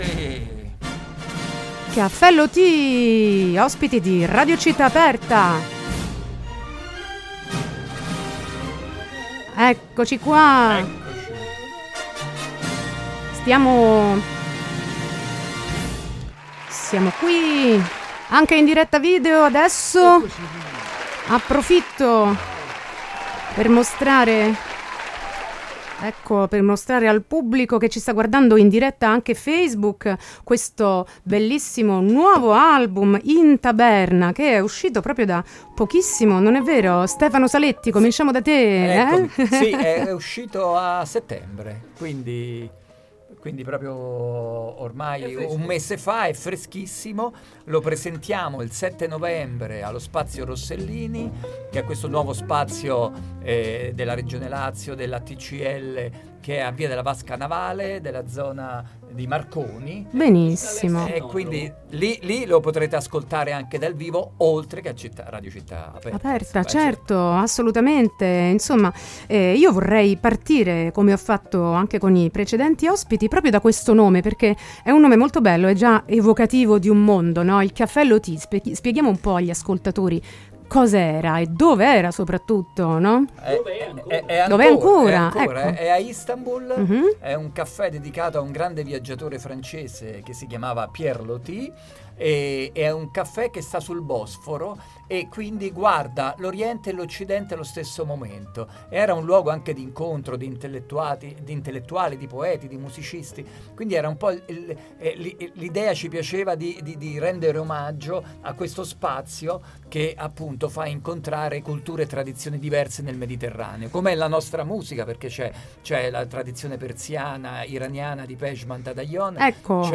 Intervista e minilive Cafè Loti 31-10-19